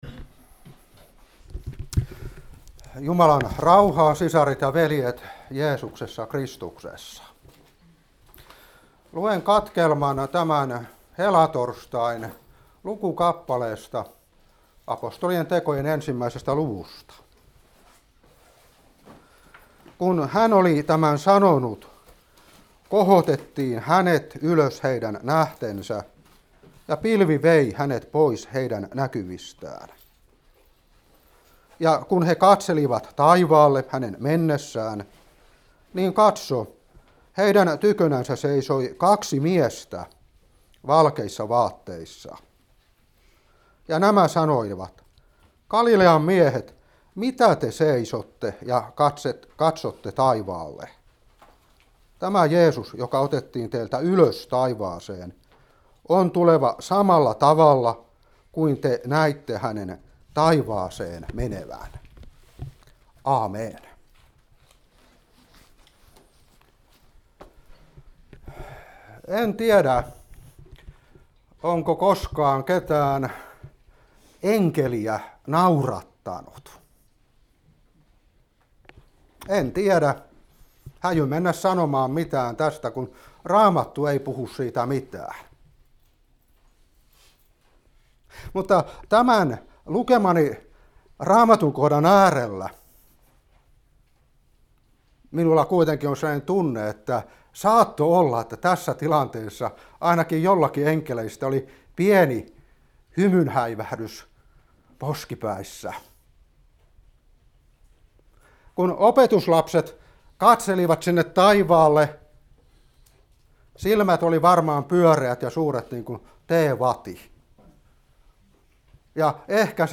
Seurapuhe 2022-5.